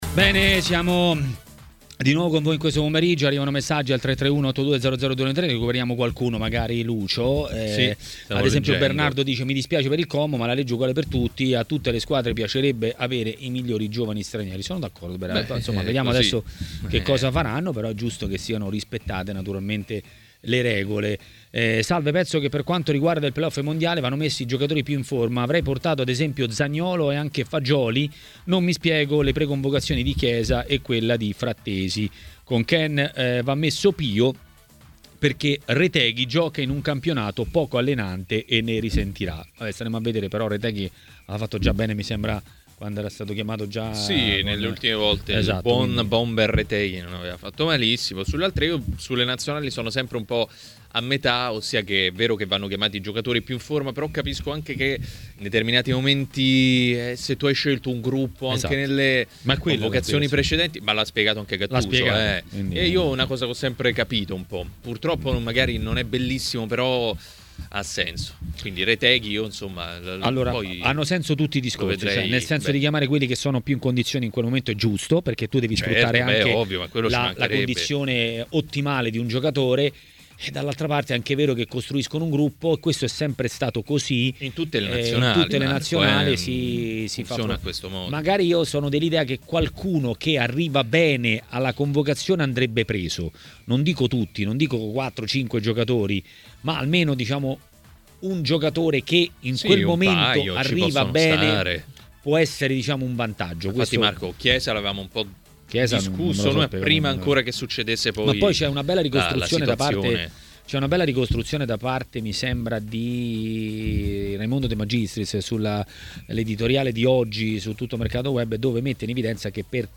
A Maracanà, nel pomeriggio di TMW Radio